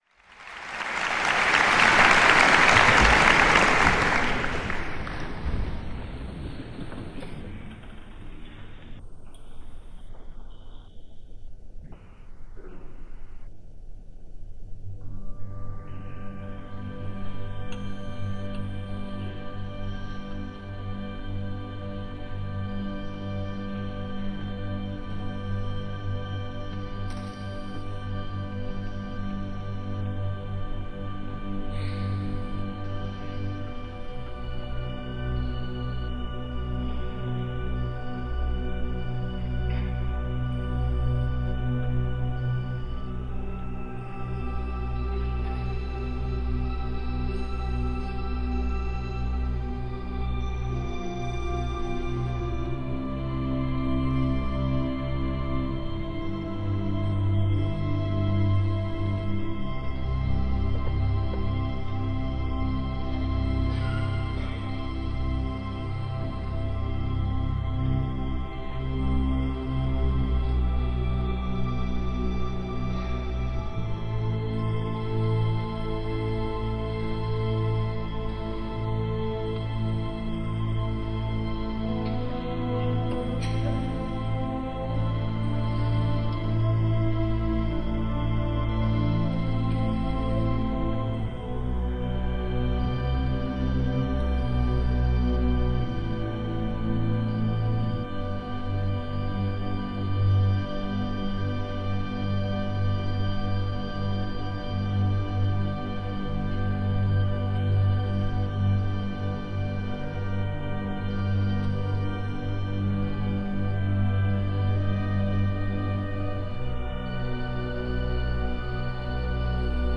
pianist
in concert from Barcelona this week